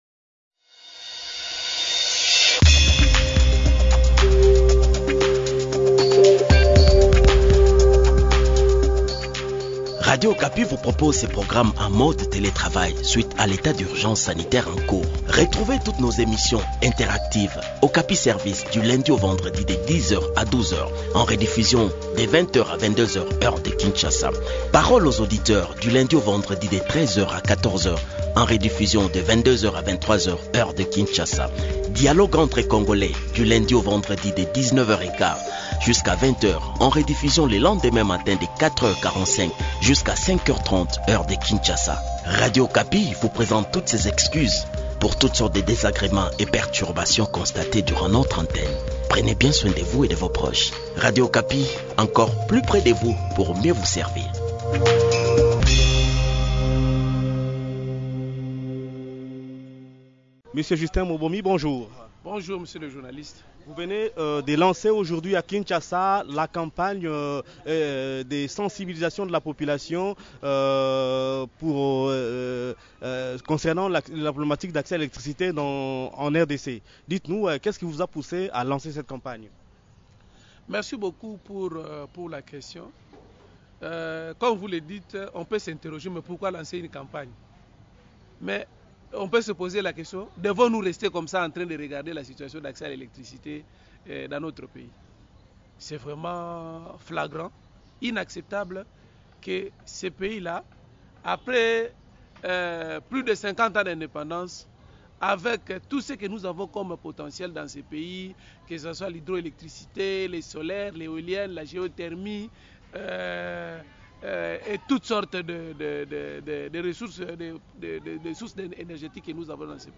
Le point sur le déroulement de cette campagne dans cet entretien